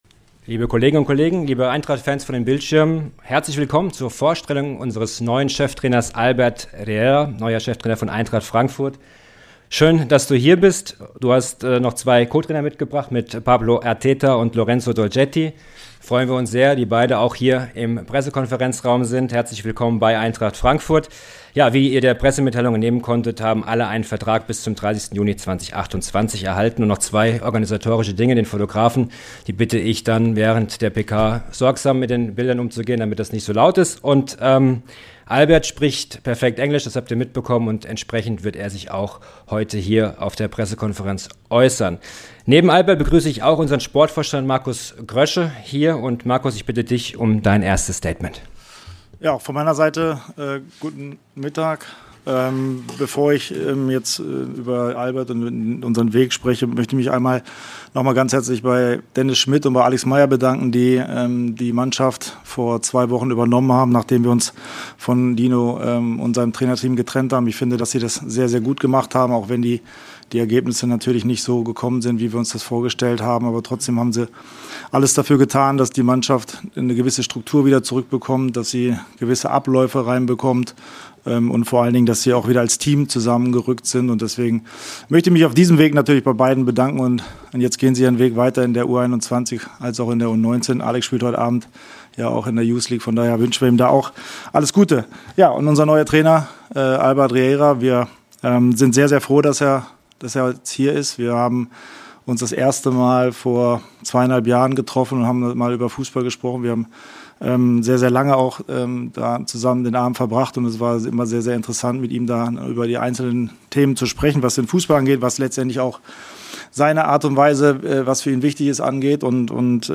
„Schmetterlinge im Bauch“ I Pressekonferenz mit Albert Riera und Markus Krösche ~ Eintracht Aktuell Podcast
Premiere für unseren neuen Cheftrainer: Bei der Pressekonferenz stellt er sich vor und beantwortet die Fragen der Journalisten, gemeinsam mit Sportvorstand Markus Krösche.